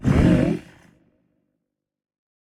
Minecraft Version Minecraft Version snapshot Latest Release | Latest Snapshot snapshot / assets / minecraft / sounds / mob / warden / listening_1.ogg Compare With Compare With Latest Release | Latest Snapshot
listening_1.ogg